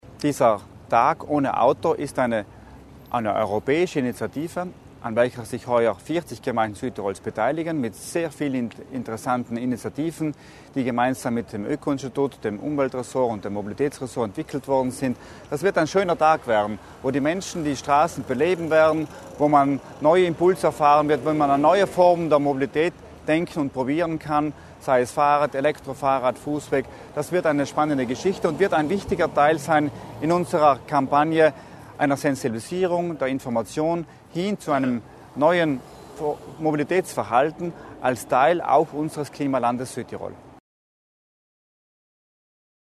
Bürgermeister Heidegger über die Zusammenarbeit mit den Gemeinden